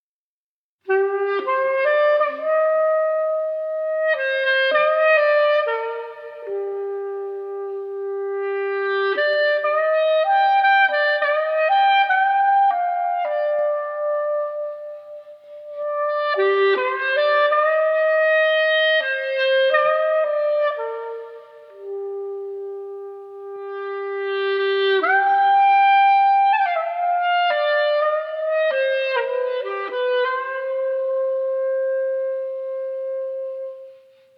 Hornlåt
Inspelad: Radio Halland